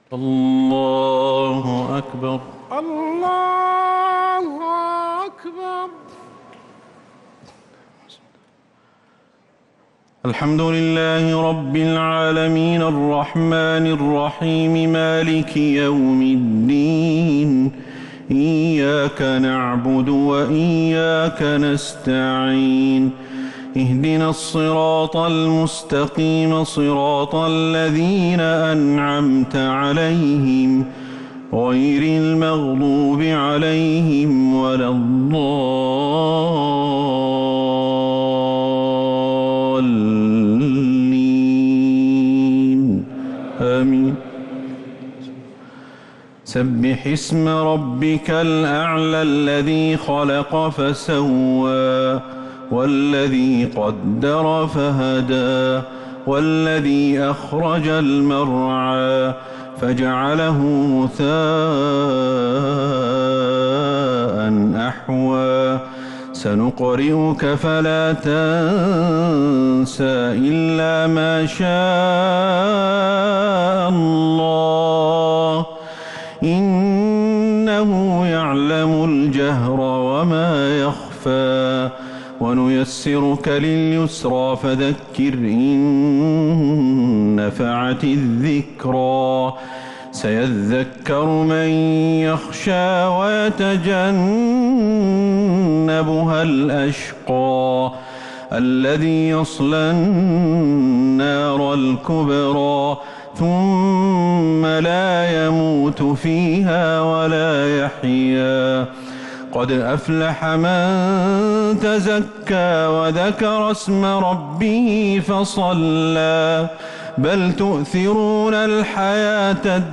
الشفع و الوتر ليلة 23 رمضان 1443هـ | Witr 23 st night Ramadan 1443H > تراويح الحرم النبوي عام 1443 🕌 > التراويح - تلاوات الحرمين